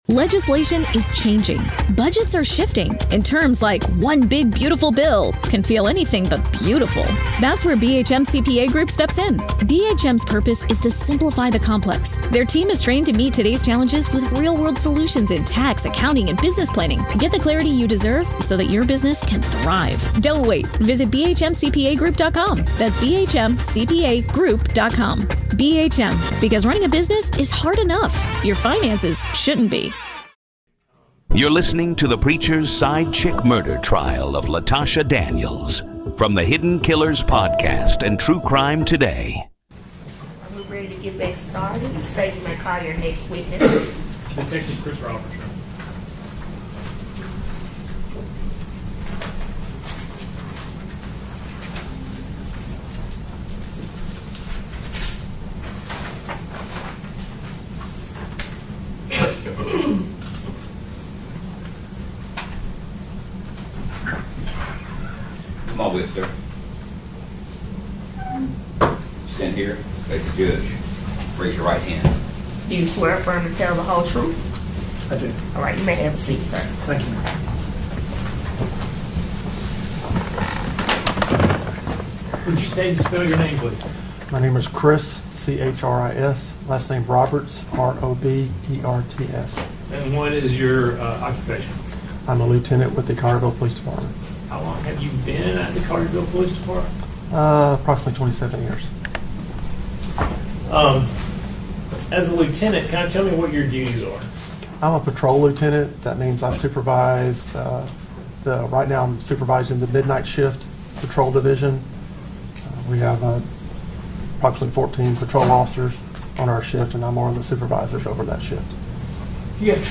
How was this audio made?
Raw, unedited courtroom coverage Each recording features the full day’s testimony, witness questioning, objections, rulings, and all live developments direct from the courtroom — presented exactly as they happened, without edits or commentary.